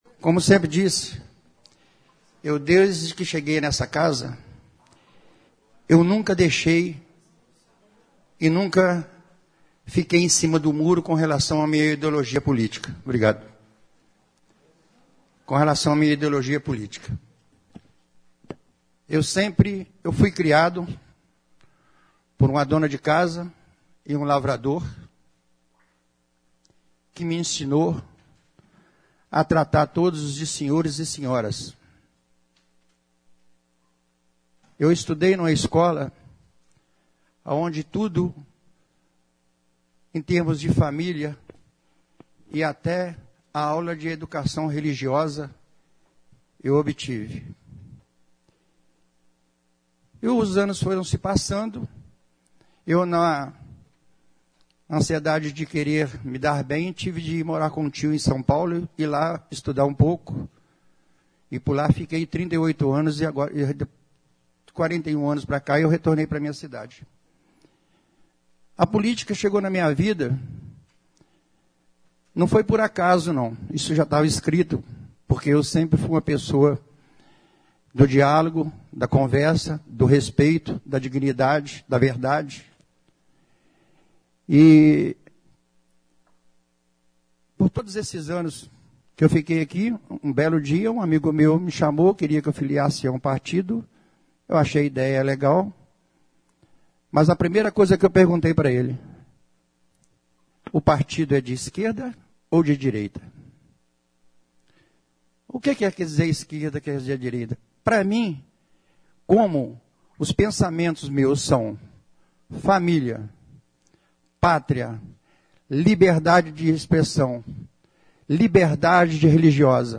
Ouça a palavra do vereador José Maria na Tribuna